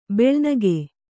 ♪ beḷnage